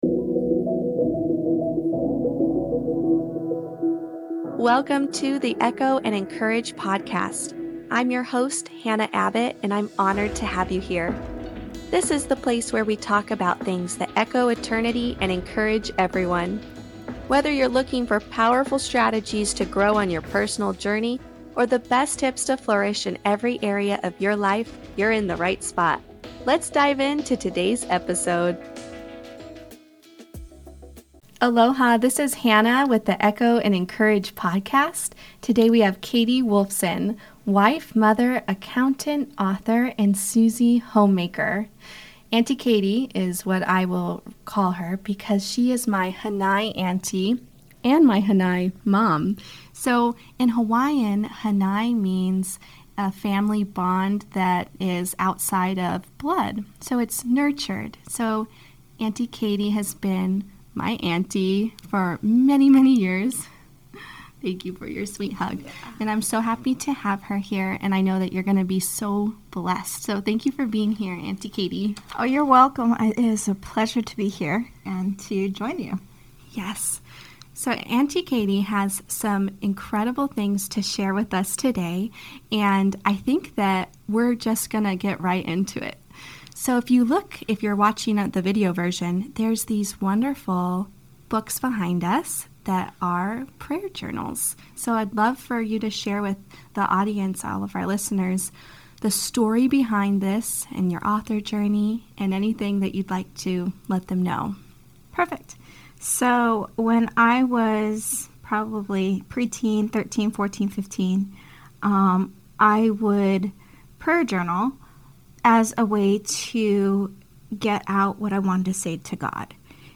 A warm hug straight from the heart.